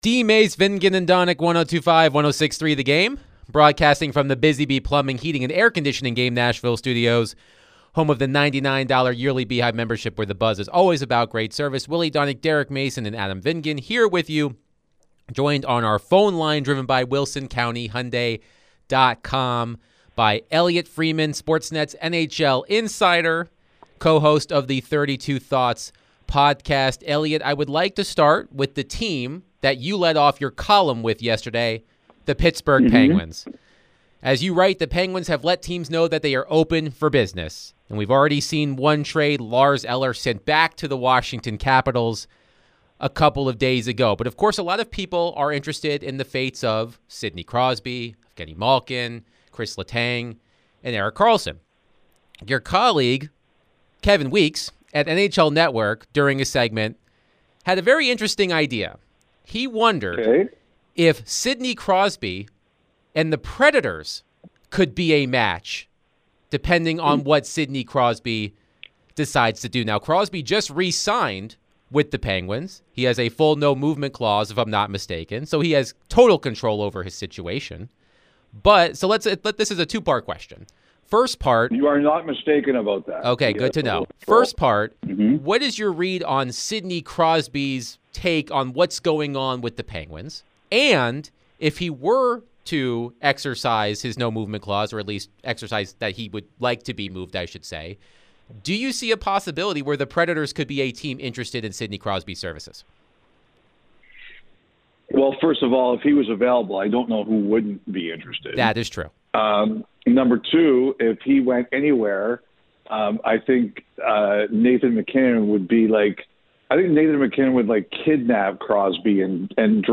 NHL Insider Elliotte Friedman joined the show to share his thoughts on the Predators and the Penguins being open for business. Could the Preds make a move for Sidney Crosby?